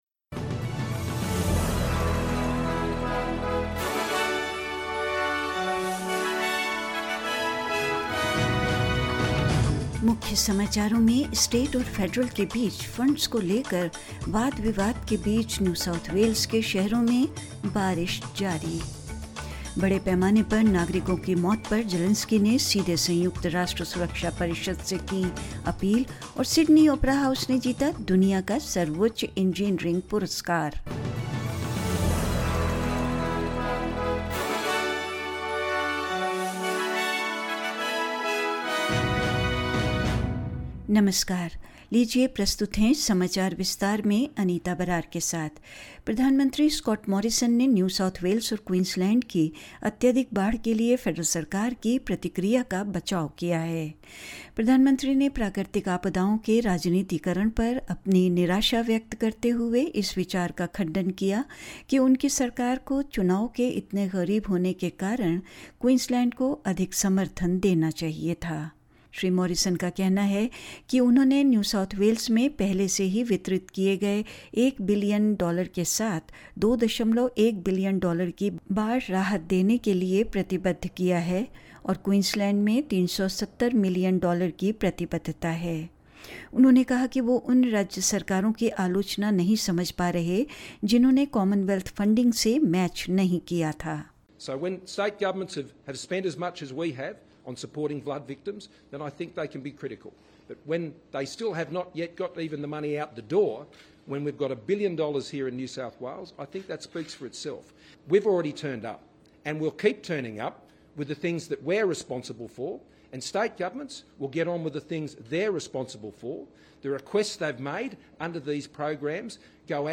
SBS Hindi News 06 April 2022: Ukraine's President appeals UN Security Council over mass civilian deaths